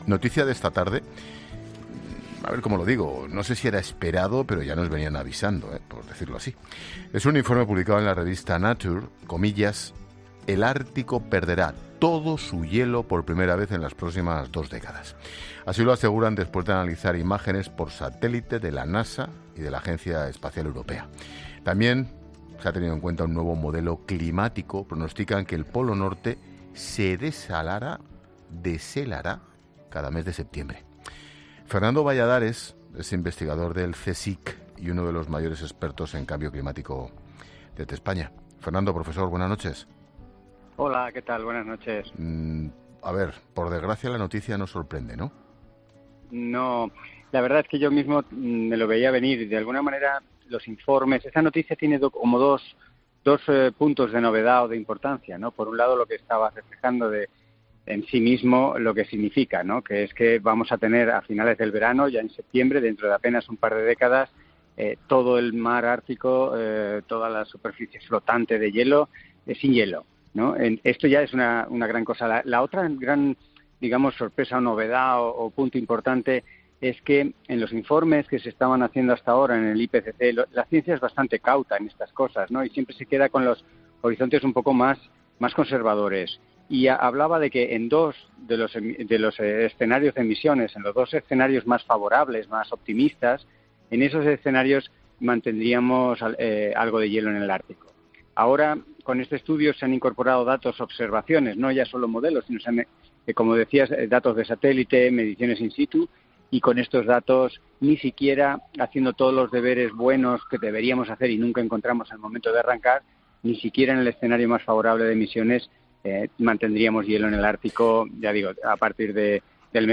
Fernando Valladares, uno de los mayores expertos en cambio climático en España, explica en La Linterna que "claro que se va a notar" el deshielo